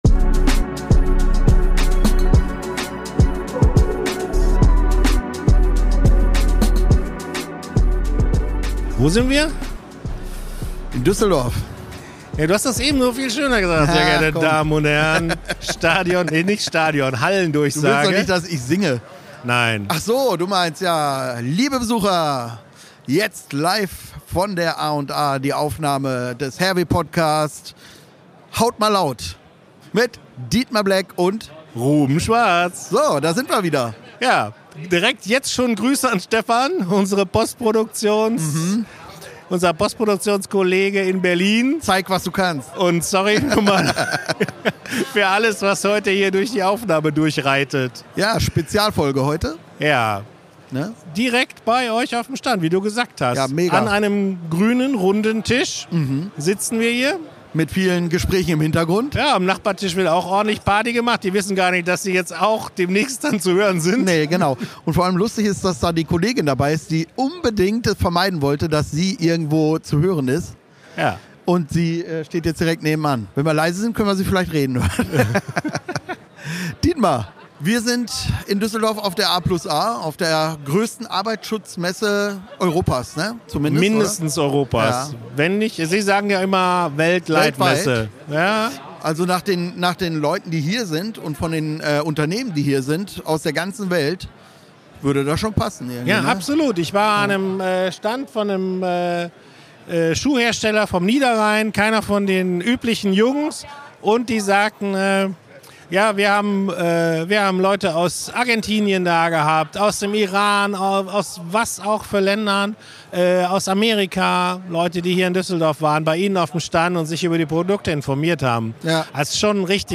Dann machen sich die beiden noch auf die Suche nach O-Tönen zur Messe.